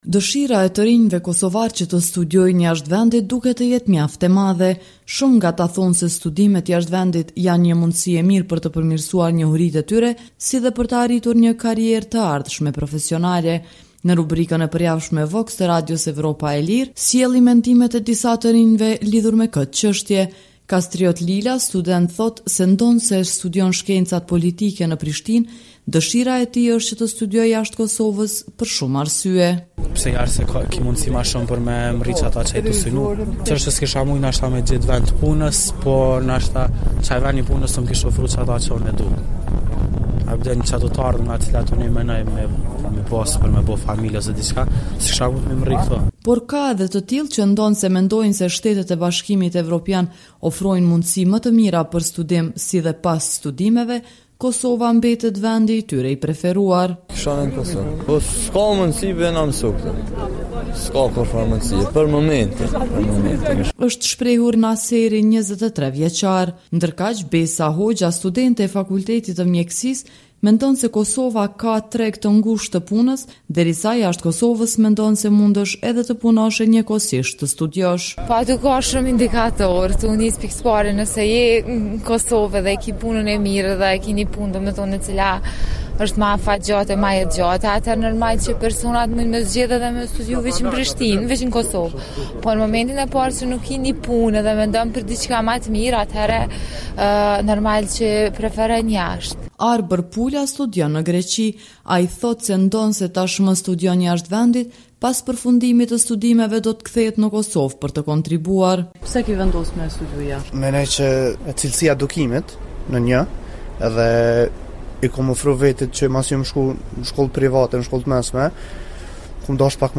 Të anketuarit për rubrikën tonë të përjavshme VOX thonë se studimet jashtë janë më cilësore, si dhe tregu i punës është më i gjerë.